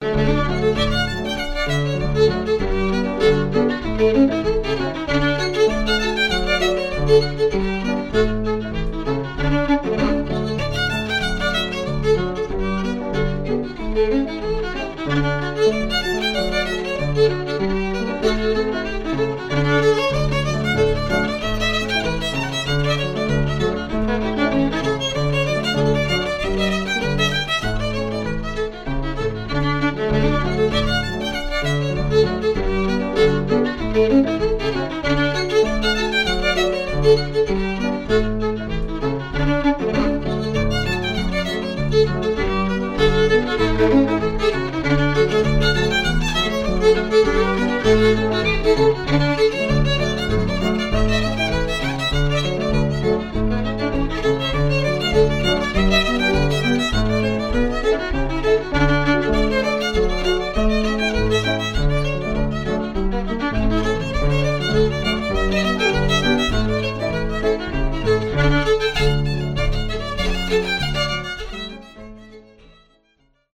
Reel
pno